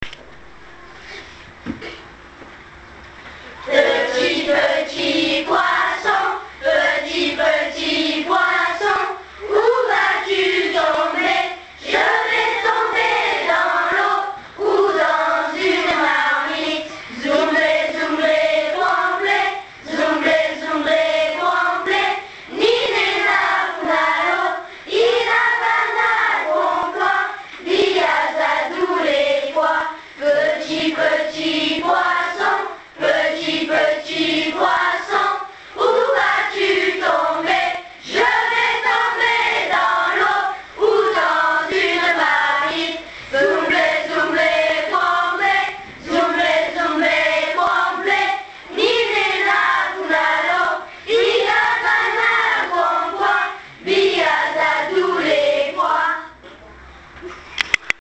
Petit poisson (Chant burkinab�) chant� par les �l�ves de l'�cole de Bapla